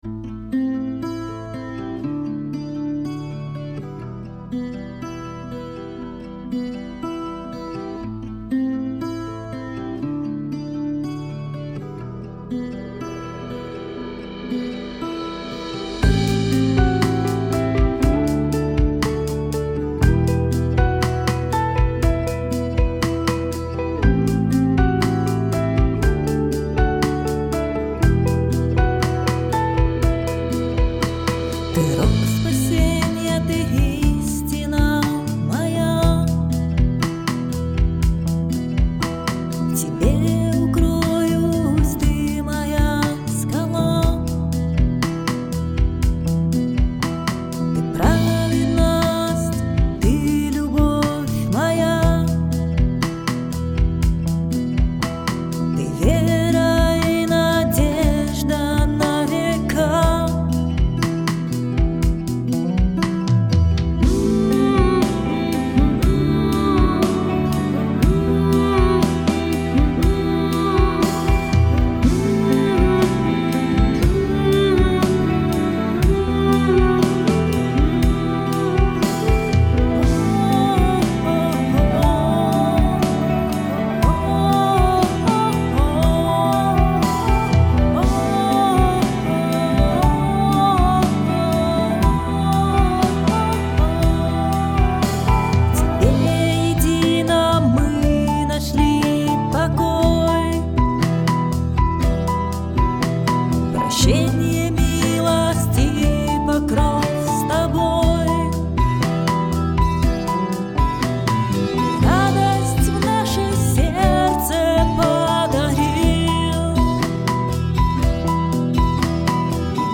песня
66 просмотров 137 прослушиваний 4 скачивания BPM: 60